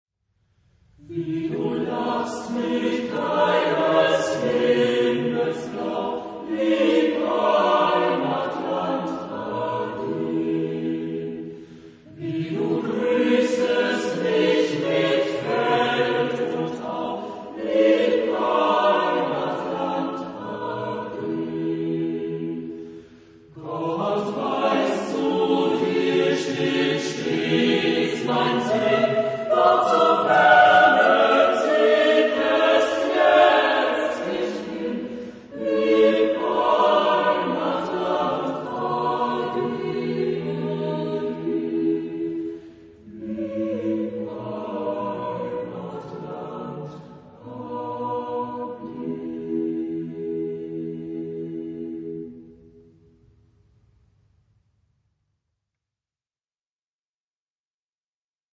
Genre-Style-Forme : Folklore ; Marche ; Chanson ; Profane
Type de choeur : SATB  (4 voix mixtes )
Tonalité : mi bémol majeur